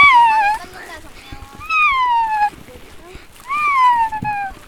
동물소리흉내03.ogg